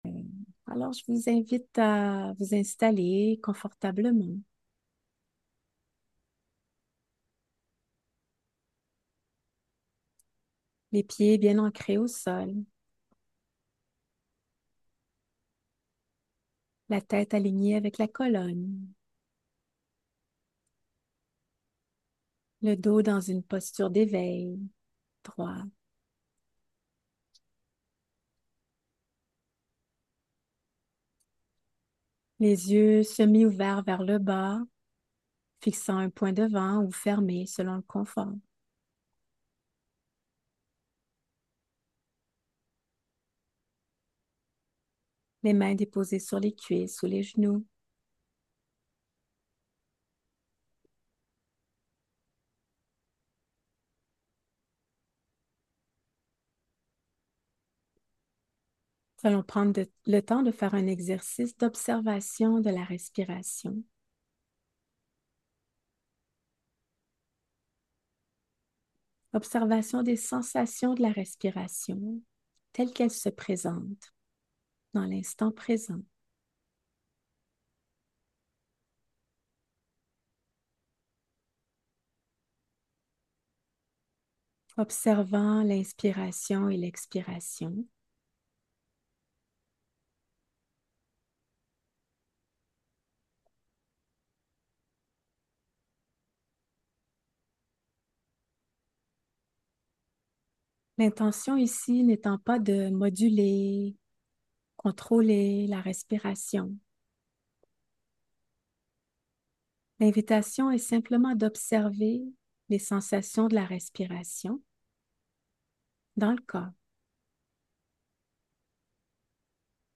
Méditation assise
S3-meditation-assisse-20-minutes-souffle-corps.mp3